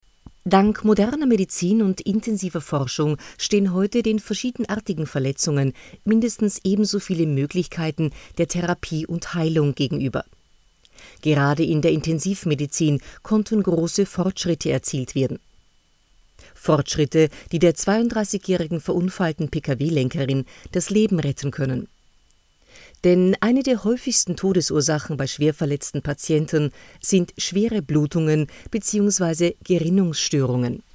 Synchronstimme, bekannt aus TV-und Radio, Moderatorin, Off-Sprecherin,Schauspielerin
Sprechprobe: Industrie (Muttersprache):